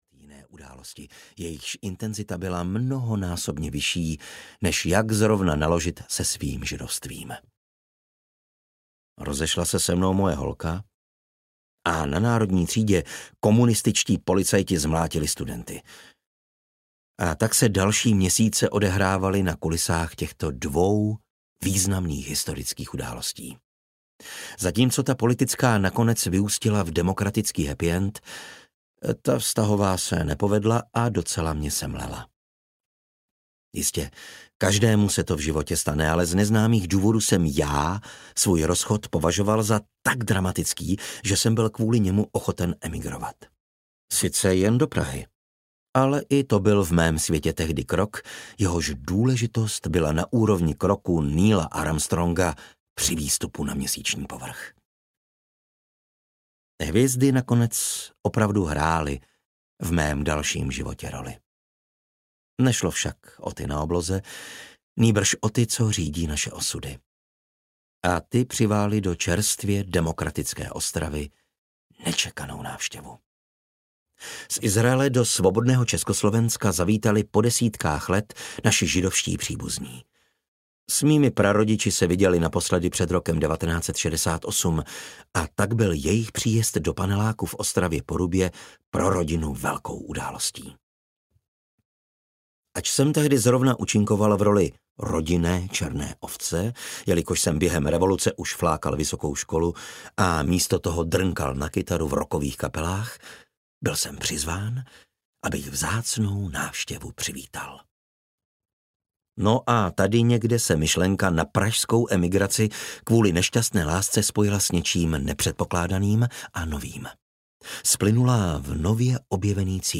Sloužil jsem v Gaze audiokniha
Ukázka z knihy